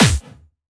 Jumpstyle Kick 3
14 AttackNoize.wav